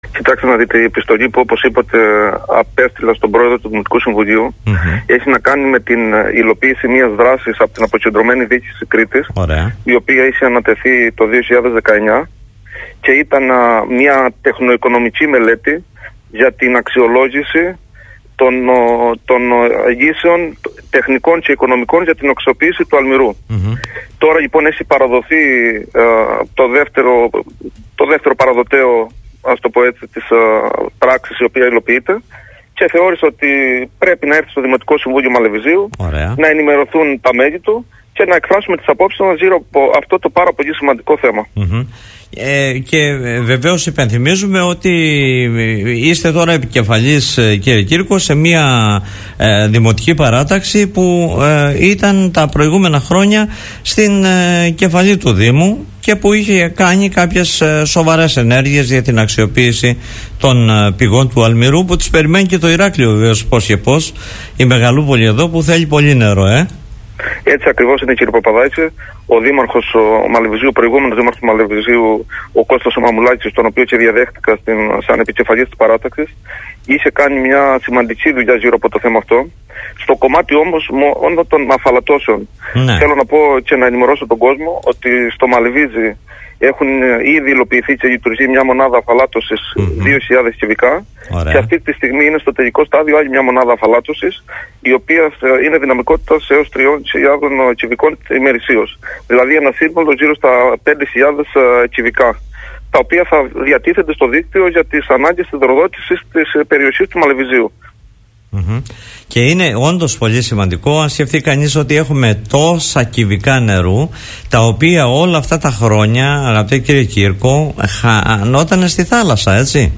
Για την επιστολή, αναφέρθηκε ο επικεφαλής της μείζονος αντιπολίτευσης του Δήμου Μαλεβιζίου Θανάσης Κύρκος, στον Politica 89.8